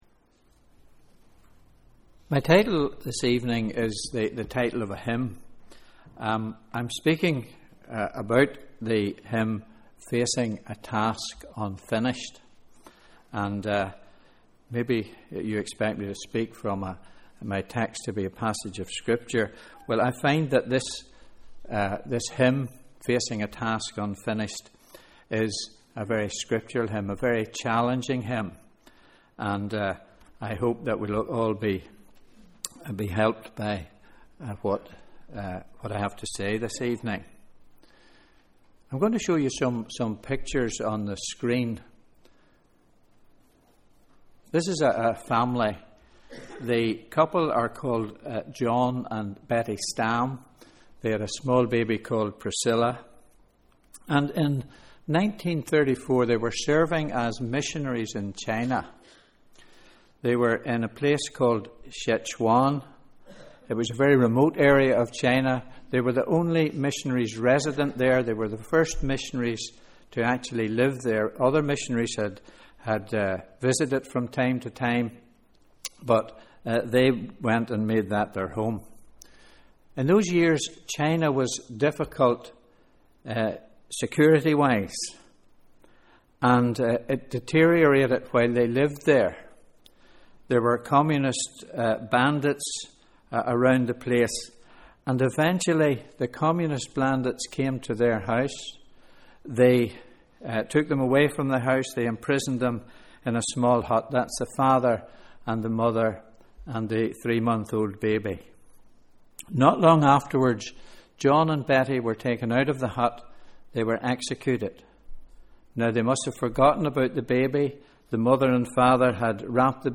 Sunday 10th March: Evening Service